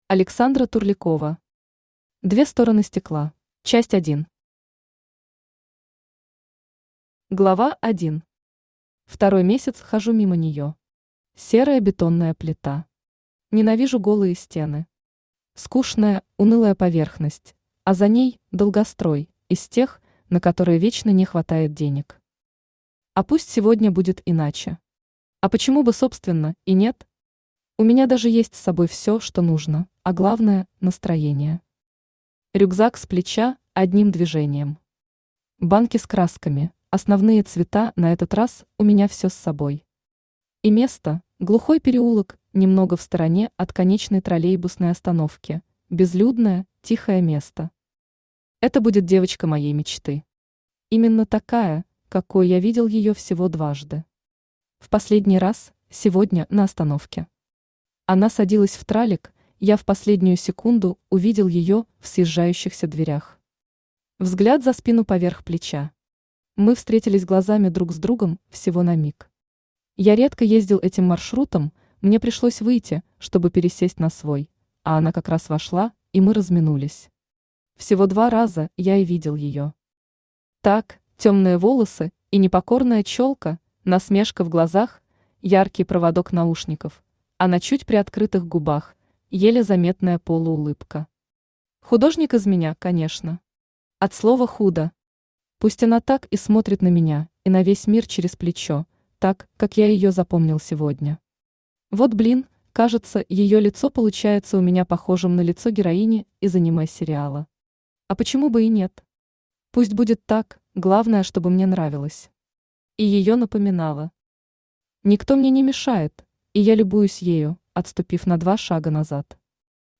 Аудиокнига Две стороны стекла | Библиотека аудиокниг
Aудиокнига Две стороны стекла Автор Александра Турлякова Читает аудиокнигу Авточтец ЛитРес.